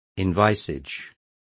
Complete with pronunciation of the translation of envisages.